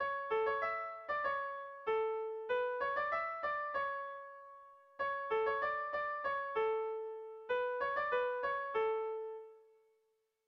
Melodías de bertsos - Ver ficha   Más información sobre esta sección
A1A2